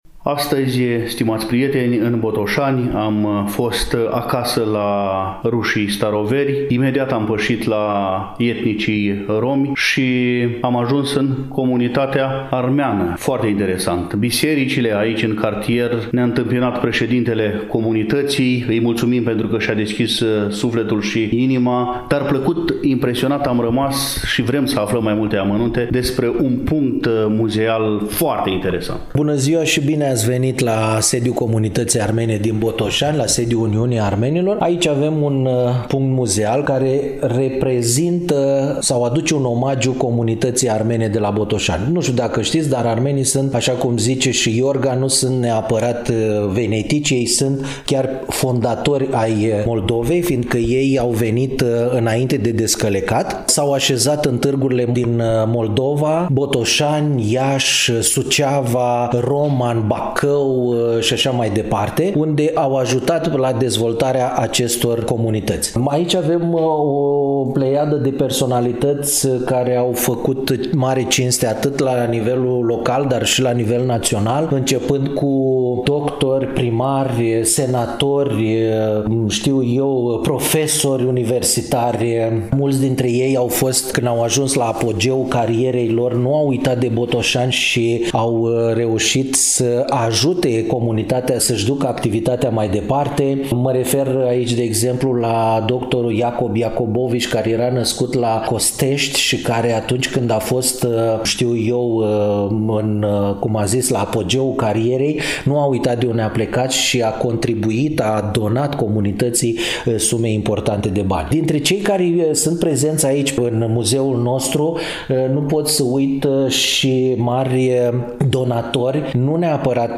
În ediția de astăzi a emisiunii Dialog intercultural, relatăm din Comunitatea Armenilor din Municipiul Botoșani, situată pe Strada Armeană, Numărul 20.
Întâi de toate pășim în incinta muzeului comunității.